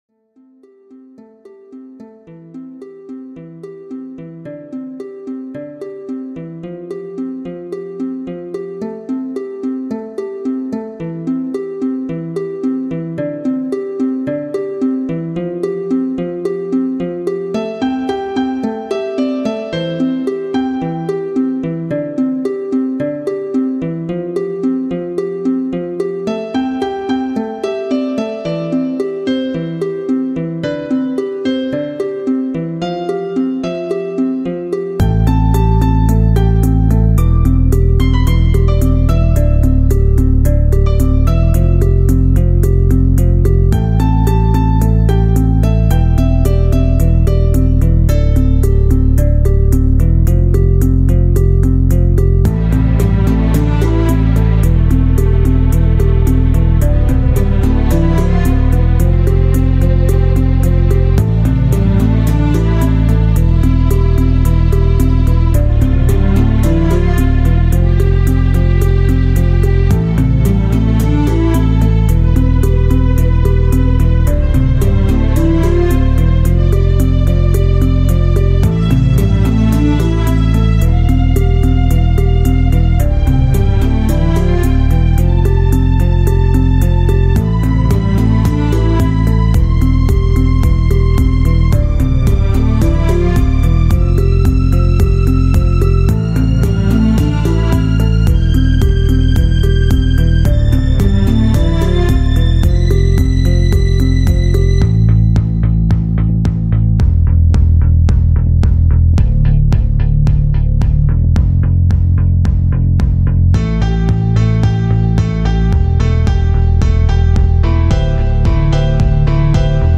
space pony music Lot of space feeling